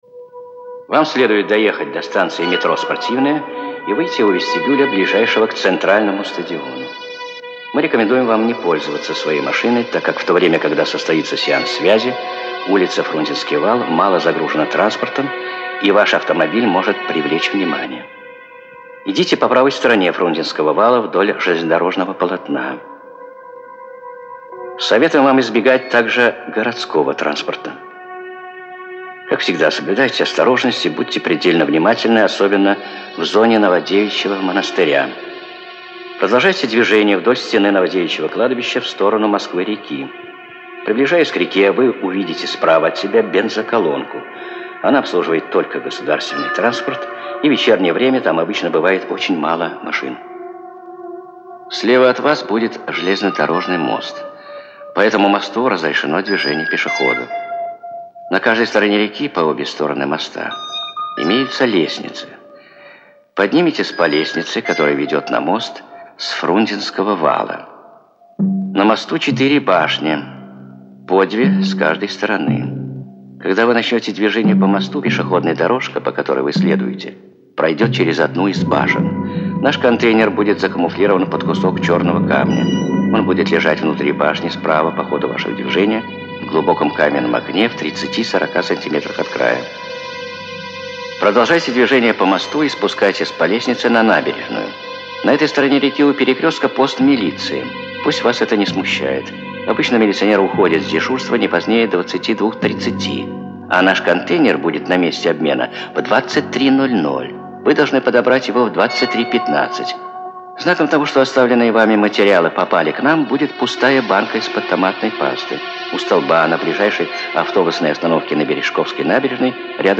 И по звуку это слышно.